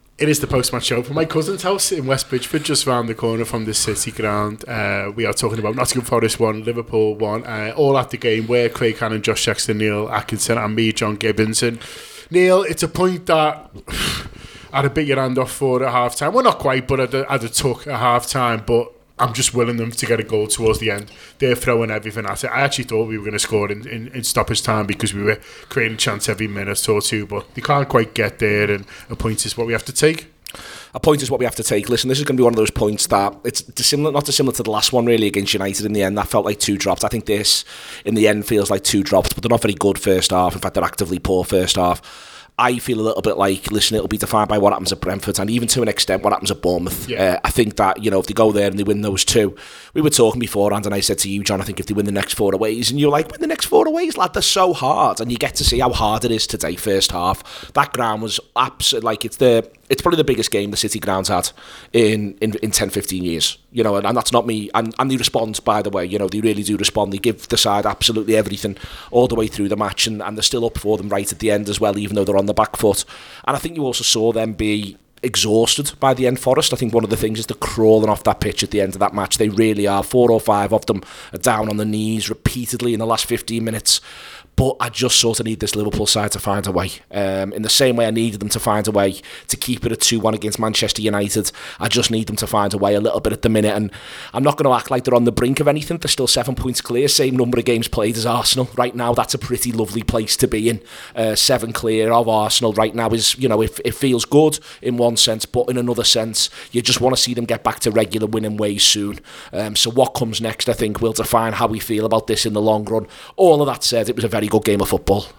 The Anfield Wrap’s post-match reaction podcast after Nottingham Forest 1 Liverpool 1 at The City Ground.